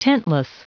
Prononciation du mot tentless en anglais (fichier audio)
Prononciation du mot : tentless